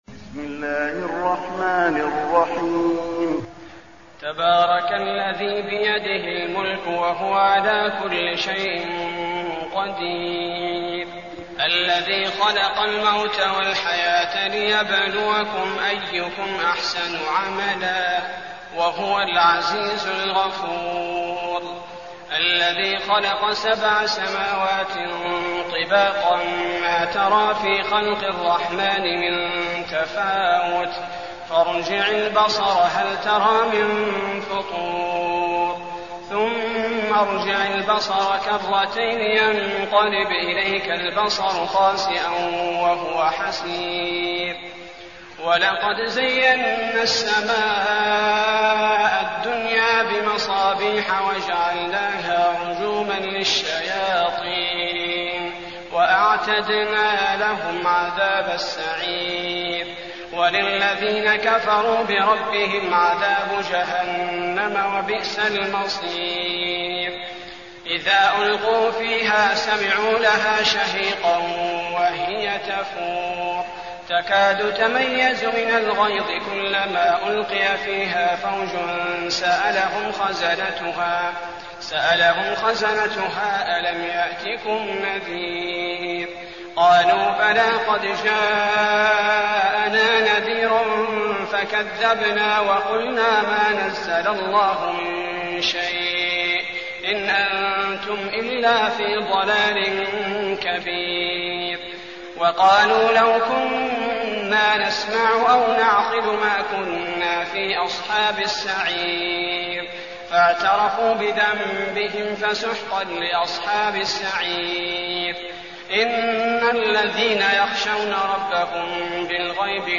المكان: المسجد النبوي الملك